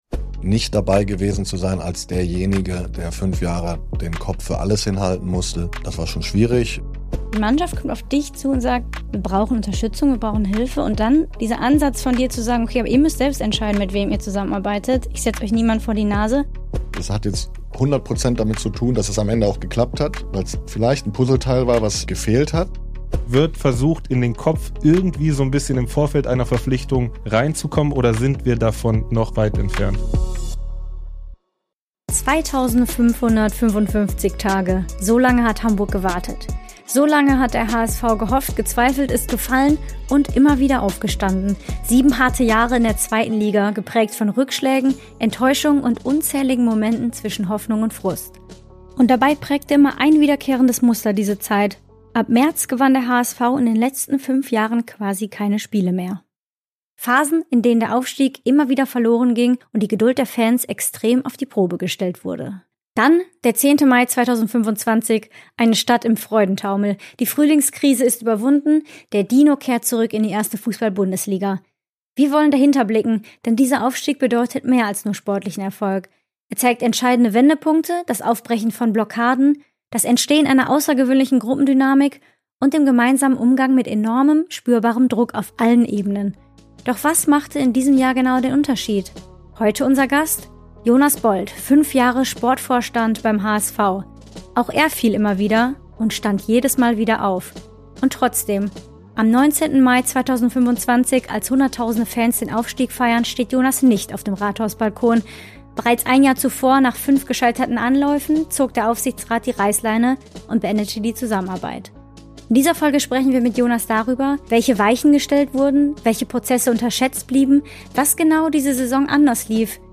Ein Gespräch über Teamdynamik, Druck, öffentliche Erwartung und darüber, warum der Erfolg oft das Ergebnis stiller Prozesse hinter den Kulissen ist.